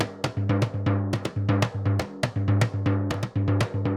Surdo 2_Salsa 120_2.wav